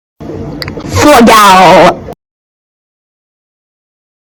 Sound Effects
Fageal Bass Boosted